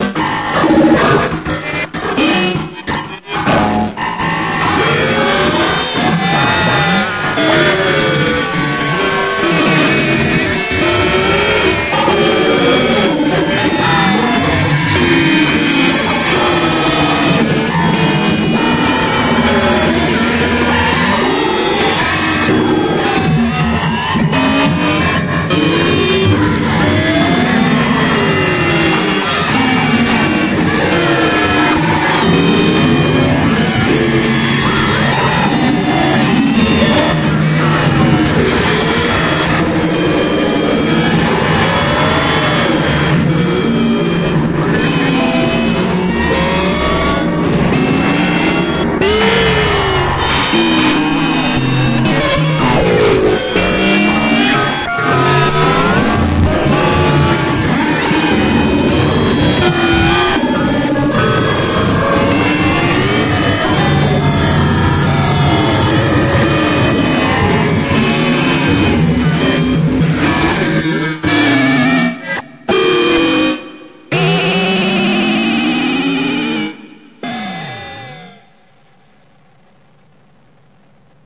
alien sounds » alien computer BSOD
>.> More guitar effects work, but this time I abused a frequency shifter to create this dreadfully earsplitting sound.
标签： bsod loud whywouldyoueverusethis painful static computer alien noise BSOD
声道立体声